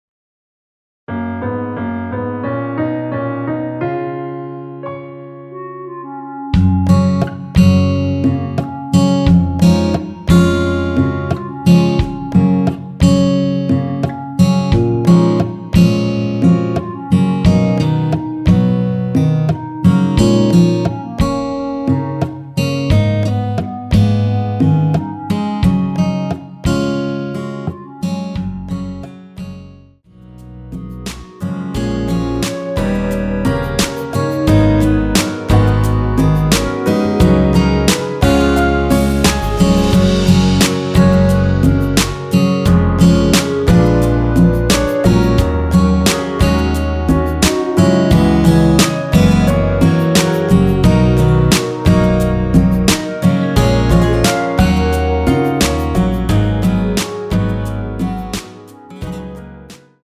원키에서(-3)내린 멜로디 포함된 MR이며 여자파트 멜로디는 없습니다.(미리듣기 참조)
F#
앞부분30초, 뒷부분30초씩 편집해서 올려 드리고 있습니다.
중간에 음이 끈어지고 다시 나오는 이유는